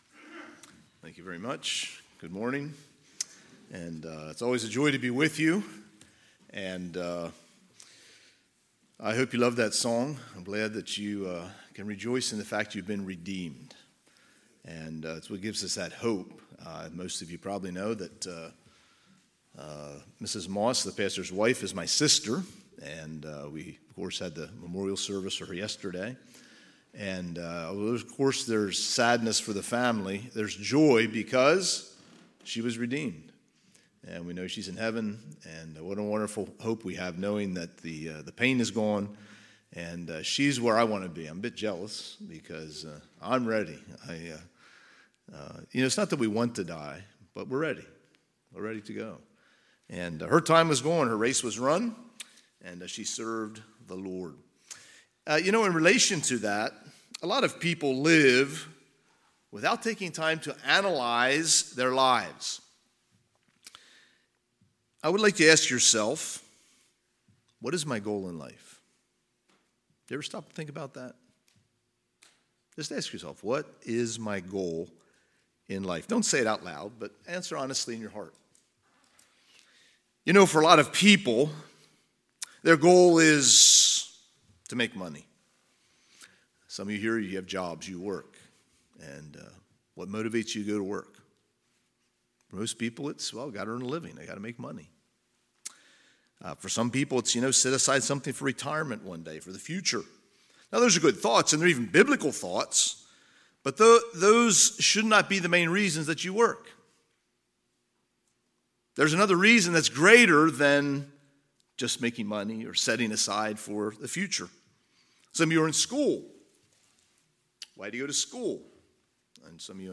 Sunday, August 27, 2023 – Sunday AM
Sermons